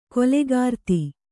♪ kolegārti